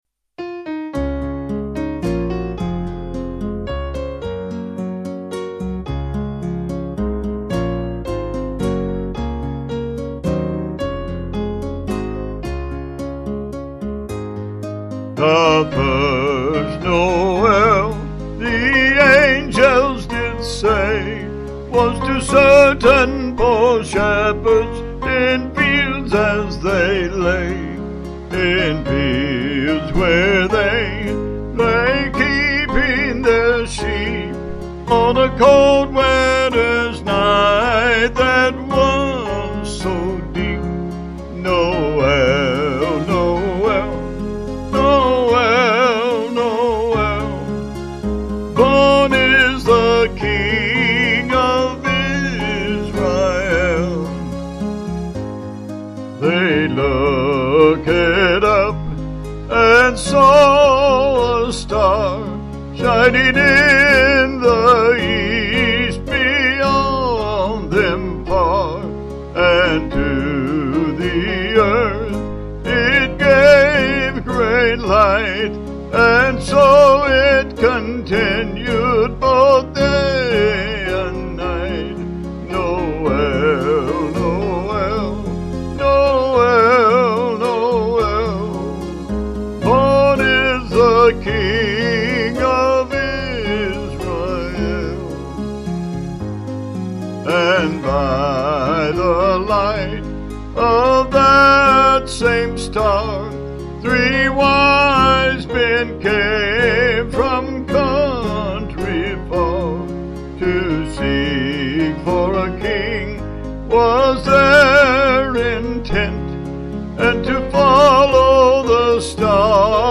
Vocals & Band
5/Db-D 280.4kb Sung Lyrics